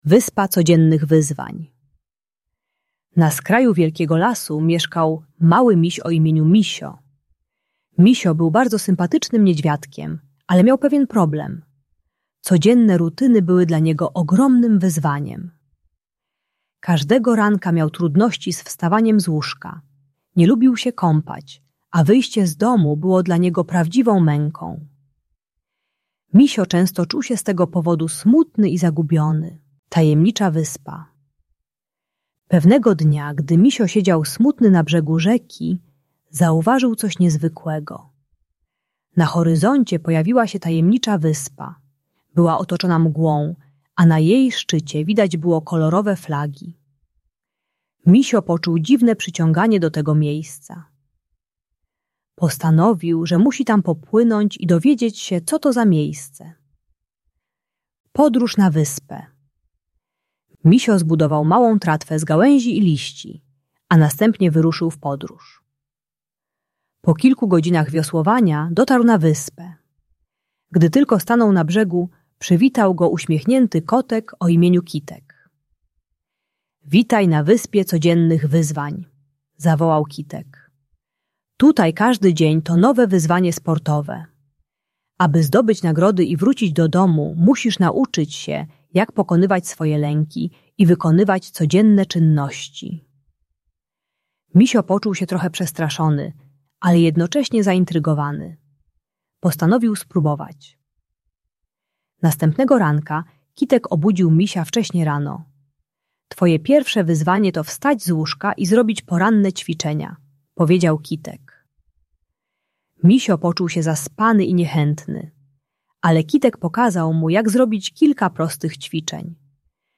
Uczy systemu małych kroków i nagradzania siebie za pokonywanie wyzwań. Audiobajka o przezwyciężaniu lęków związanych z codziennymi obowiązkami.